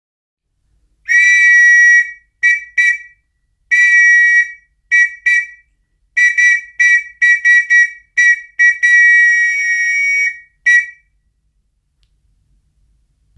木笛
二色の音が優しく響くマサイのホイッスル。
素材： 木